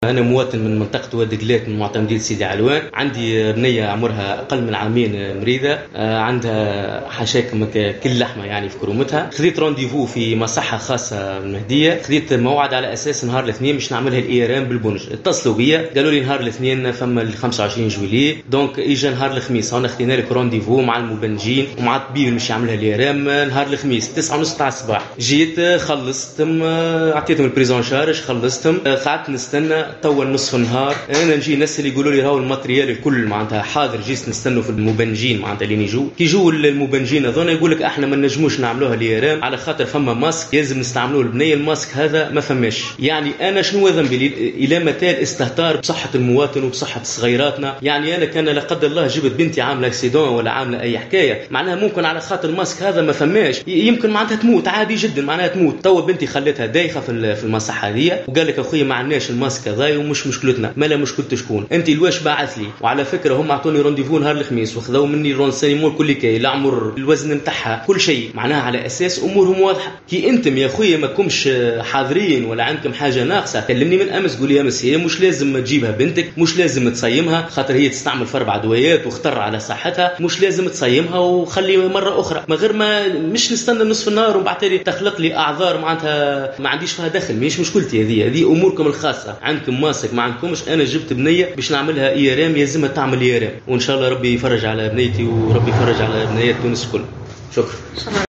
وأوضح المواطن في تصريح ل “ام اف ام”، أنه تم في مرحلة أولى تأجيل موعد التصوير بالرنين المغناطيسي (IRM)، إلى يوم 28 جويلية 2022، وبالتالي يجب عليها أن تصوم لتتمكن من القيام بها، إلا أنه وبعد أن توجّه إلى المصحة وقدم كافة الأوراق وقام باستخلاص الفاتورة أكدوا له أن المصحة لا تملك آلة تخدير.